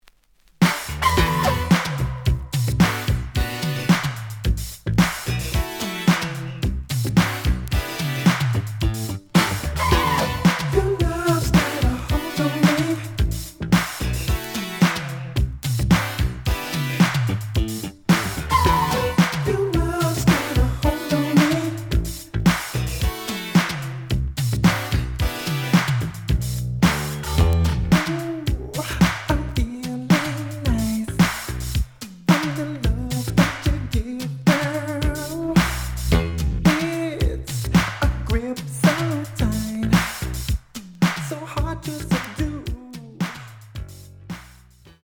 The audio sample is recorded from the actual item.
●Genre: Soul, 80's / 90's Soul
Edge warp. But doesn't affect playing. Plays good.